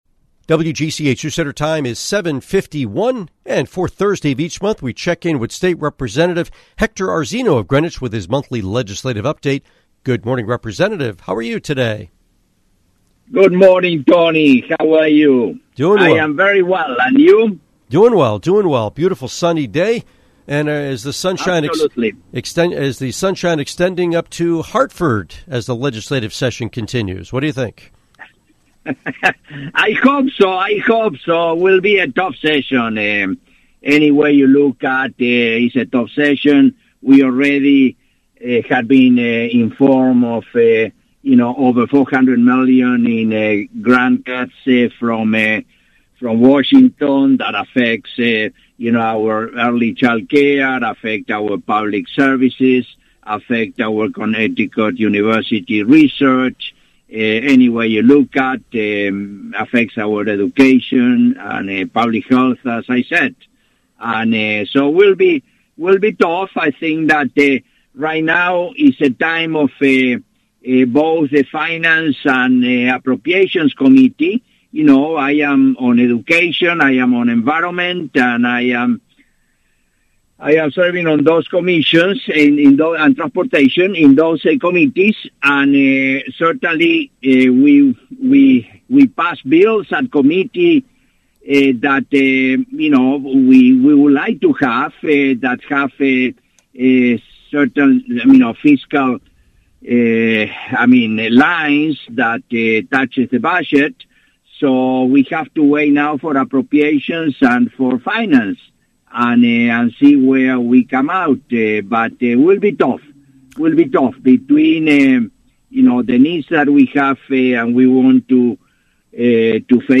Interview with State Representative Hector Arzeno